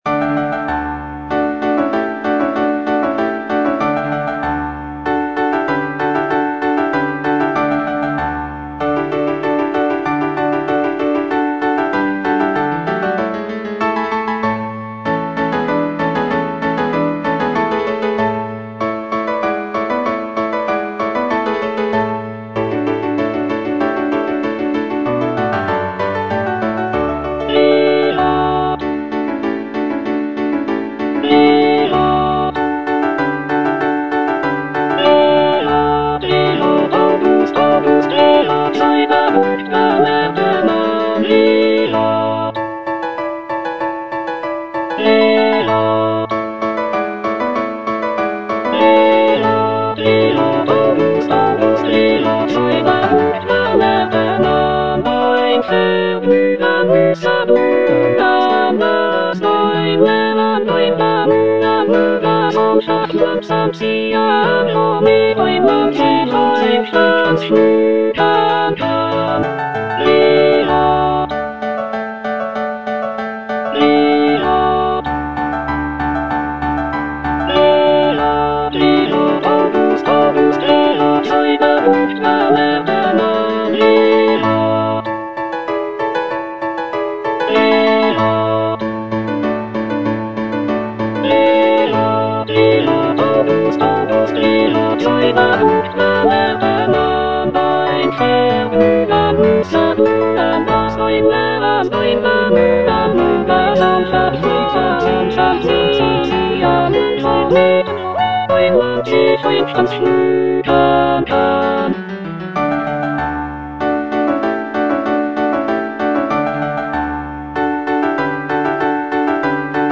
Choralplayer playing Cantata
The cantata features a dialogue between the wind god Aeolus and the river god Alpheus, celebrating the prince's virtues and rulership. The music is lively and celebratory, with intricate counterpoint and virtuosic vocal lines.